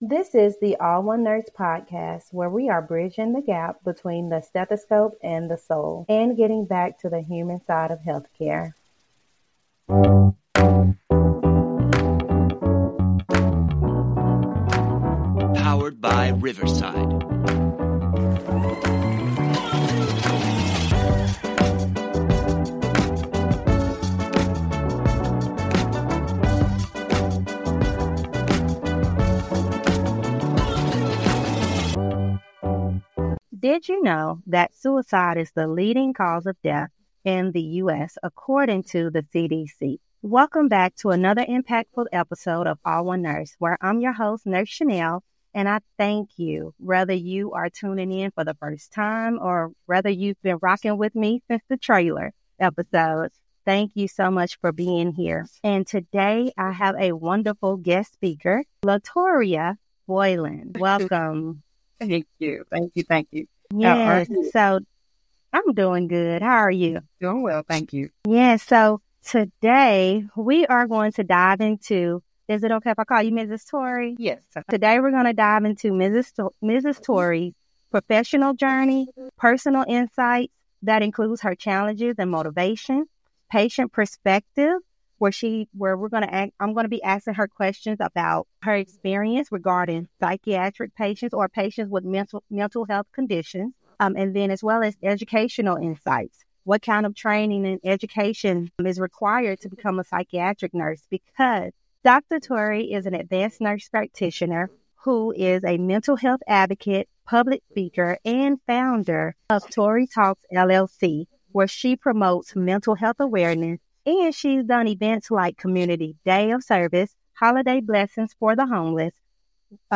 The conversation emphasizes the need for more mental health professionals, effective strategies for non-psychiatric nurses, and the significance of self-care in the demanding field of psychiatric nursing.